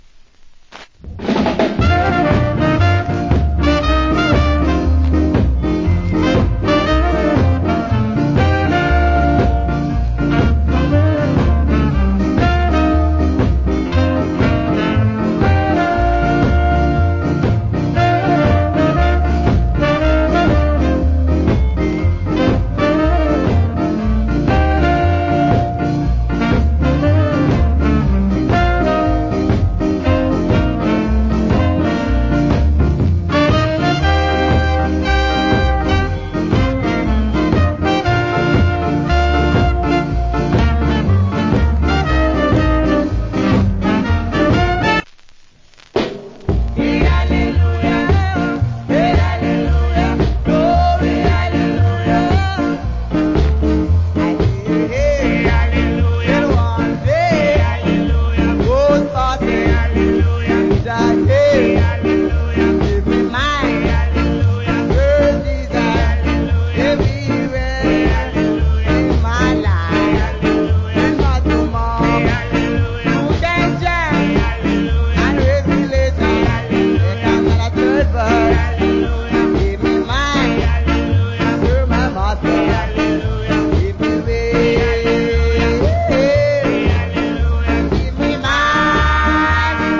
Great Ska Inst.